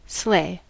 Ääntäminen
IPA : /sleɪ/